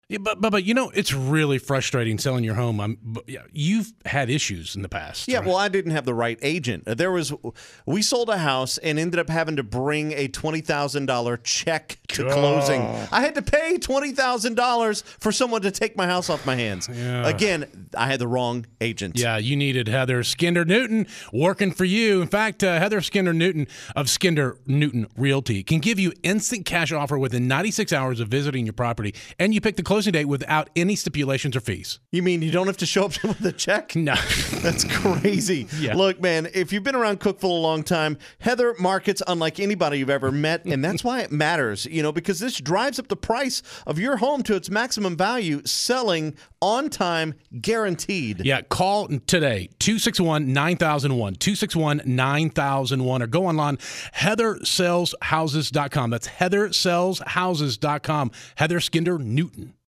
Celebrity Real Estate Agent Endorsements (Celebrity Cameos for Realtors) Celebrity Endorsement Agency | Radio & Television Experts